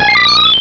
pokeemerald / sound / direct_sound_samples / cries / unown.aif